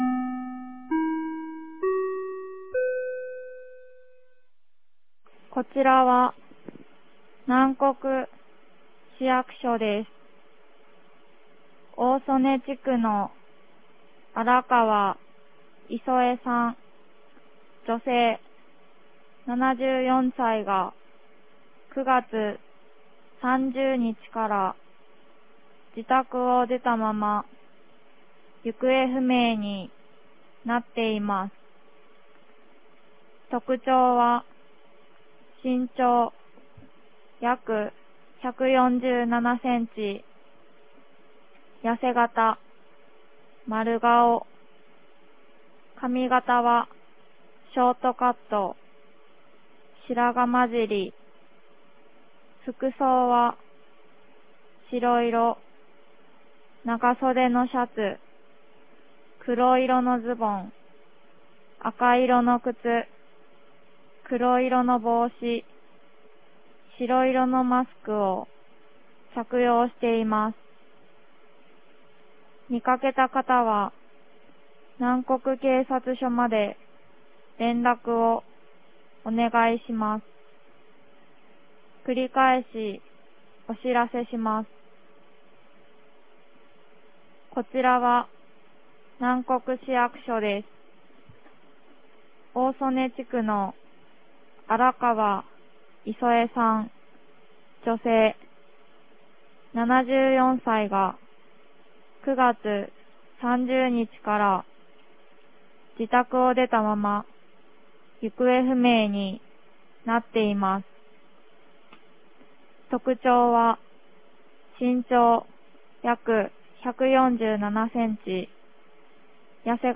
2022年10月03日 16時02分に、南国市より放送がありました。